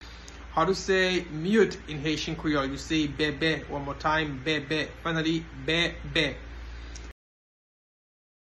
Pronunciation:
Mute-in-Haitian-Creole-Bebe-pronunciation-by-a-Haitian-Teacher.mp3